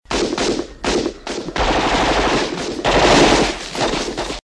PROGRESSIVE GUNFIRE.mp3
Original creative-commons licensed sounds for DJ's and music producers, recorded with high quality studio microphones.
progressive_gunfire_wk6.ogg